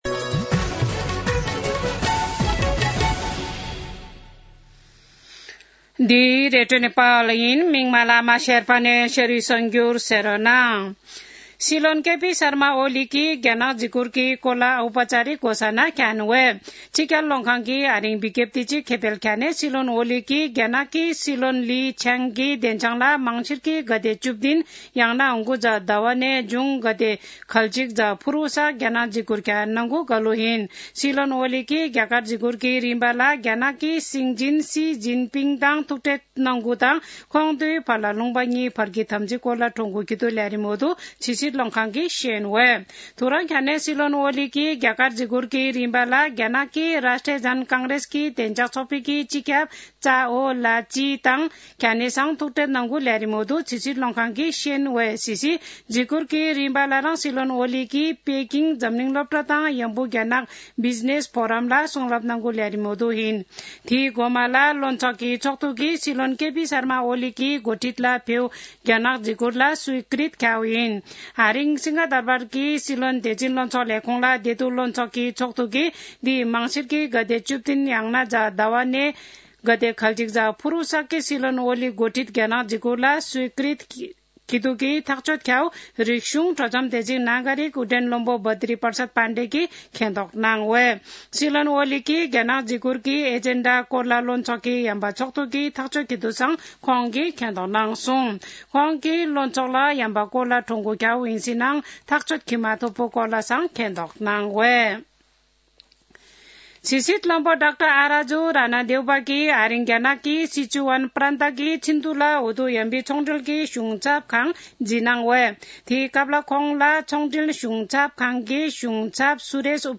शेर्पा भाषाको समाचार : १५ मंसिर , २०८१